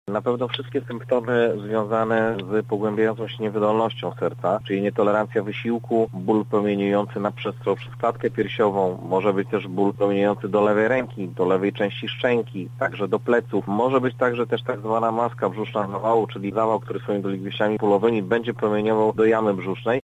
mówi ratownik medyczny